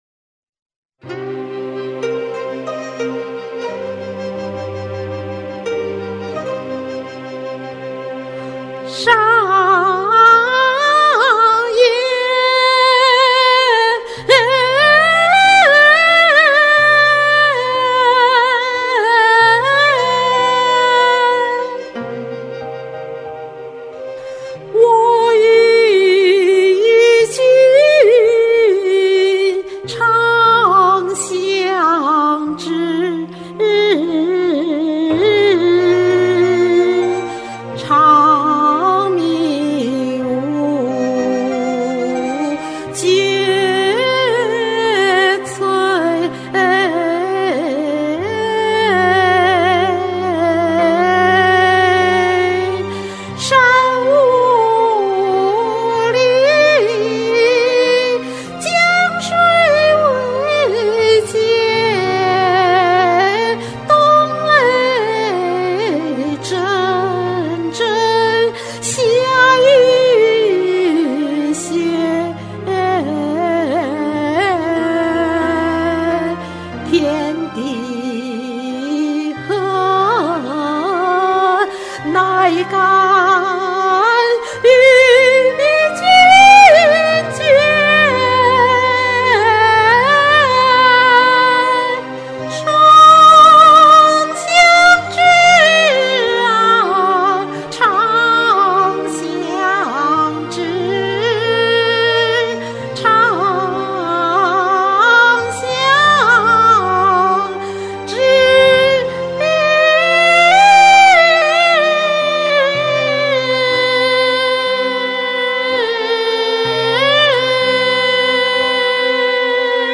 古代愛情歌曲
這次因為錄不成歌，只好拋出自己平時練歌的作業了。
中氣好足哦，腔調也拿得有模有樣的，好嘗試！
這是崑曲的韻味啊，好聽！
很有爆發力的女高音啊！